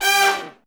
G4 POP FAL.wav